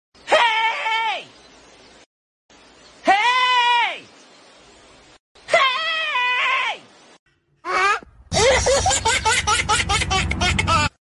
Goat Tries To Speak Human ! Sound Effects Free Download